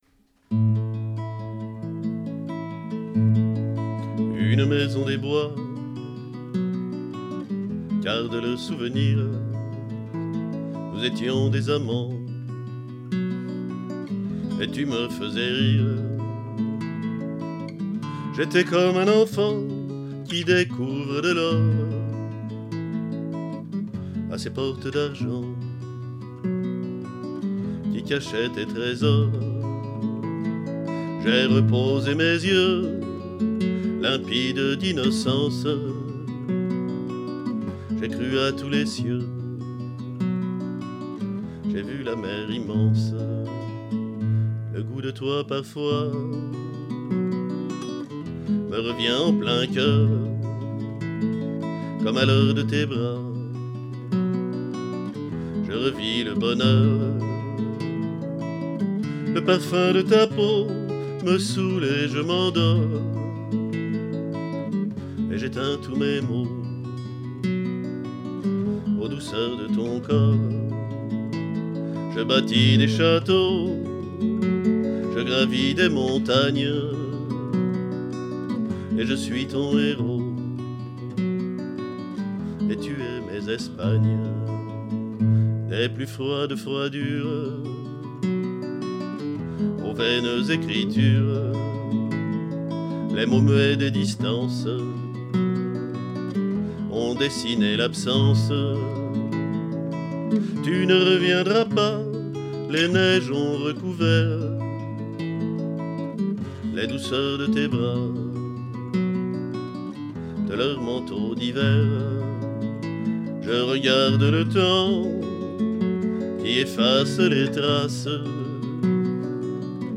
guitare